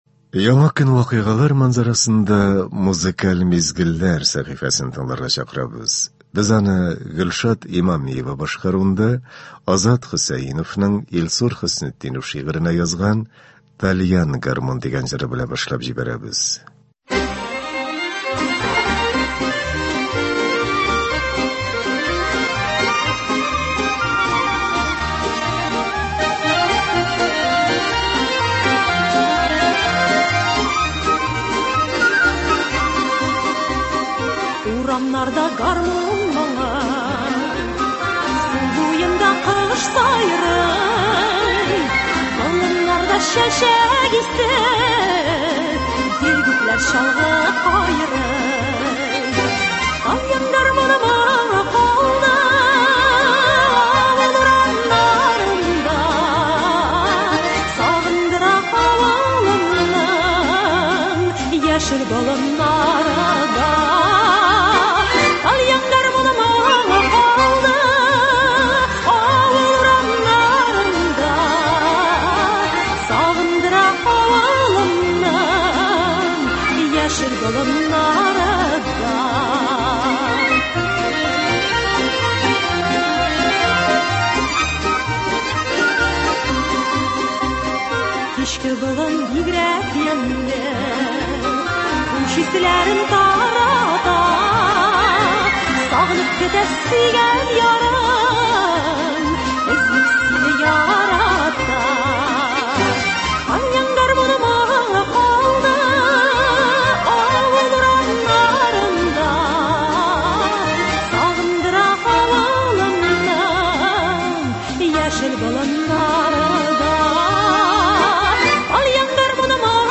Иртәбезне күтәренке кәеф, таныш моңнар белән башлыйбыз.